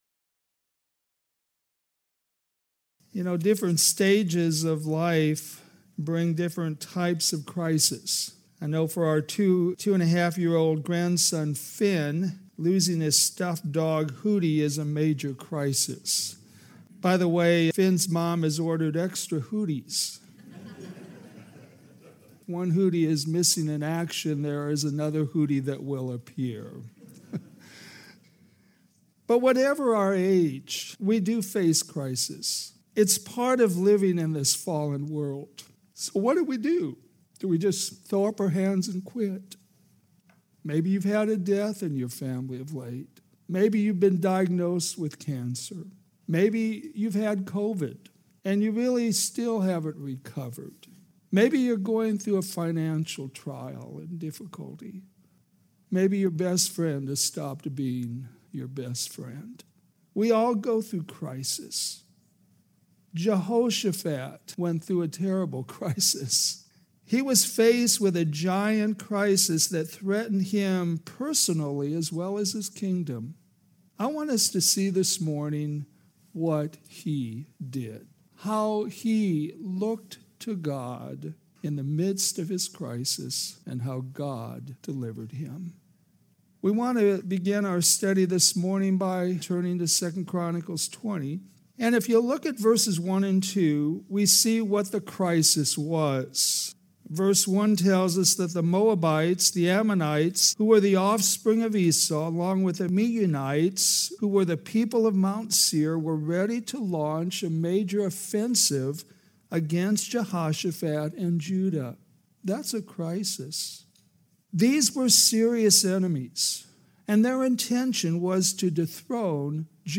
All Sermons - Westside Baptist Church